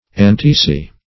Antiscians \An*tis"cians\, Antiscii \An*tis"ci*i\, n. pl. [L.